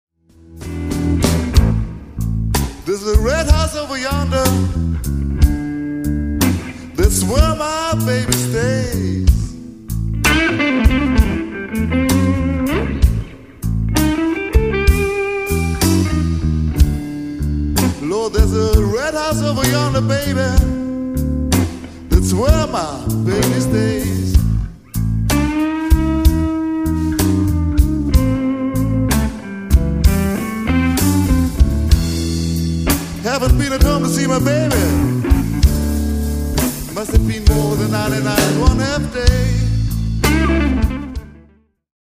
Recorded live at Brucknerhaus, Linz/Austria 8.11.2000.
guitars, lead vocals
bass, vocals
drums